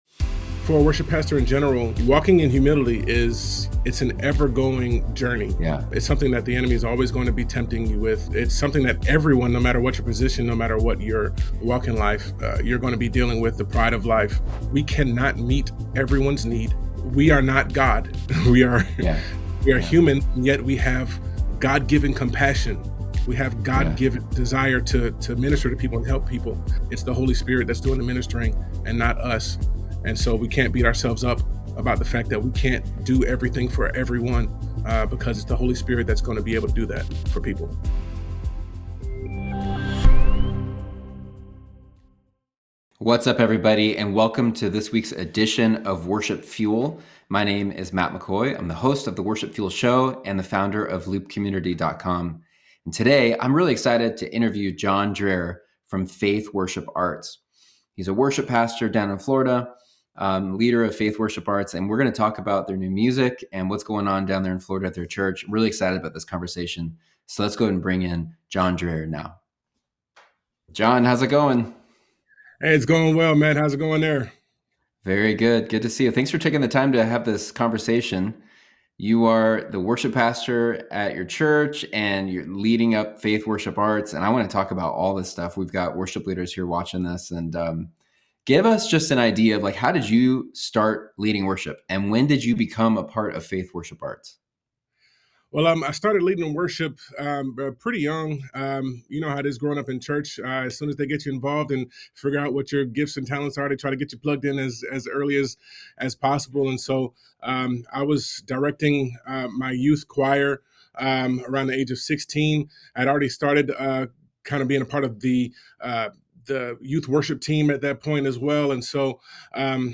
On this latest episode, we are blessed to have a conversation